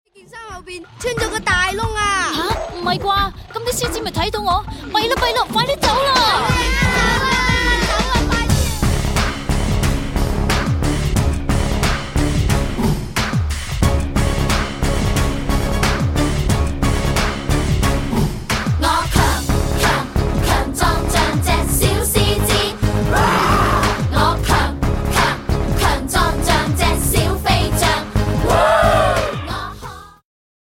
充滿動感和時代感
有伴奏音樂版本